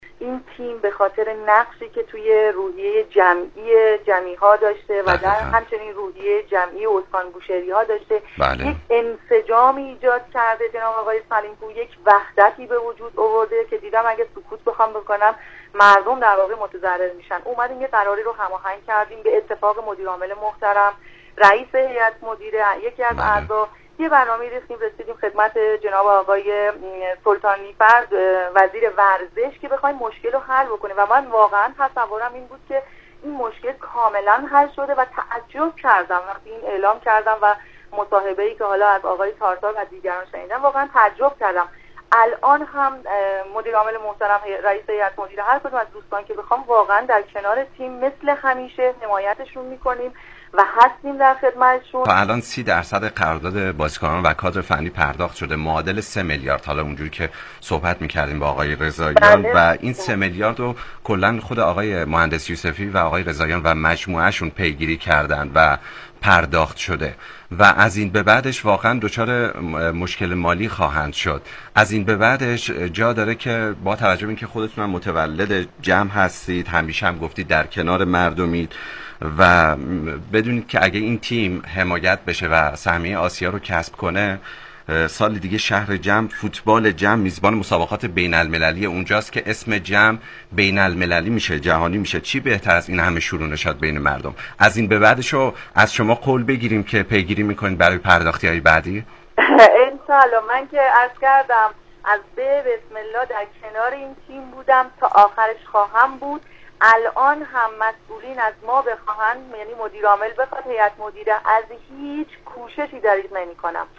از تیم پارس جنوبی جم در هر شرایطی حمایت می‌کنم + مصاحبه صوتی